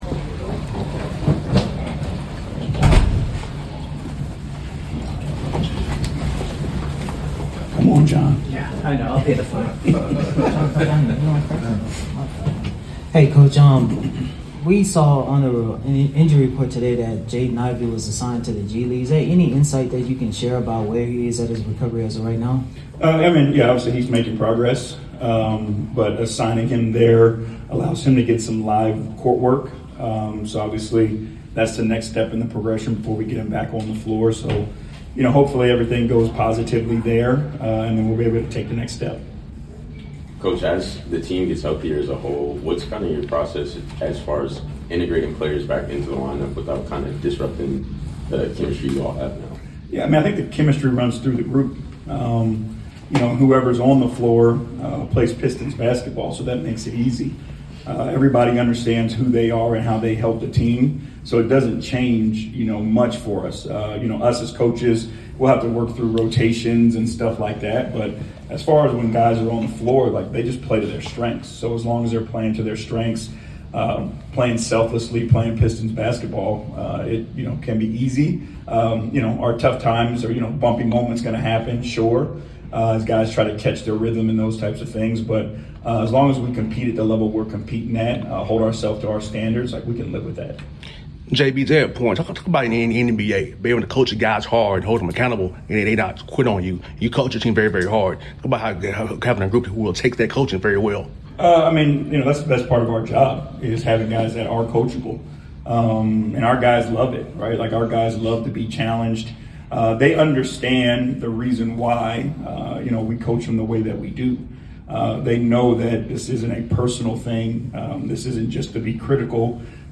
11-18-25 Detroit Pistons Coach J.B. Bickerstaff Pregame Interview
Detroit Pistons Coach J.B. Bickerstaff Pregame Interview before taking on the Atlanta Hawks at State Farm Arena.